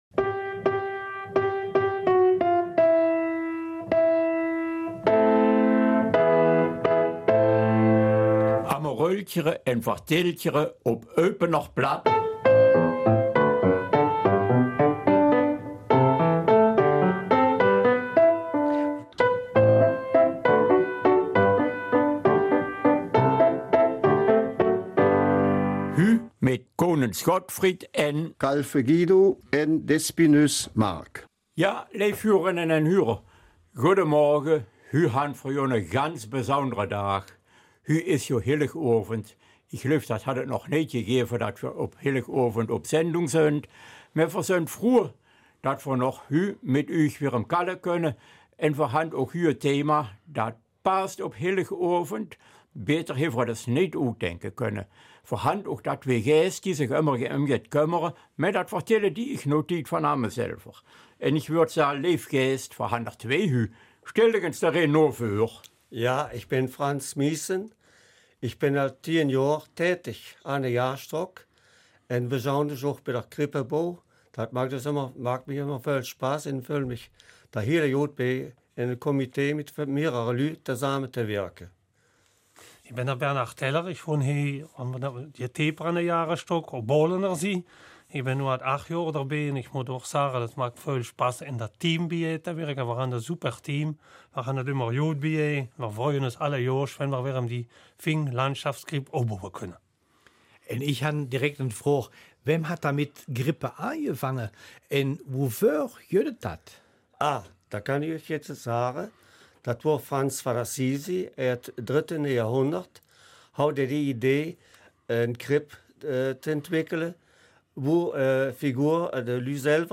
Eupener Mundart: Es weihnachtet sehr
Heute sind Krippen mit der Darstellung Jesu Geburt nicht mehr wegzudenken. Ein Grund Krippenbauer, stellvertretend für alle dieser Zunft, ins Studio einzuladen.